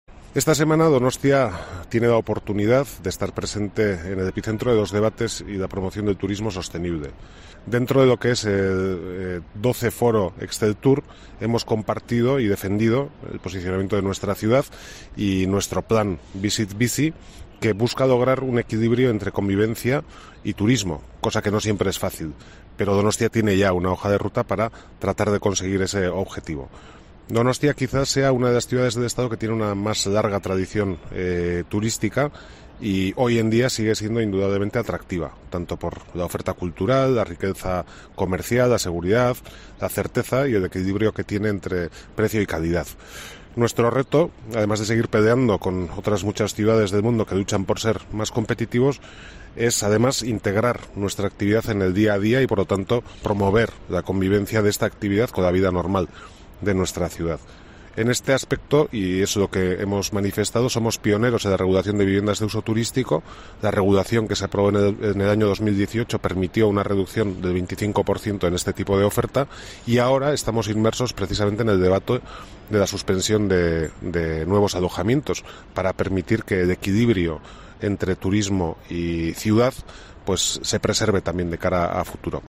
Eneko Goia, alcalde de San Sebastián sobre el Plan de turismo "Visit-Bizi"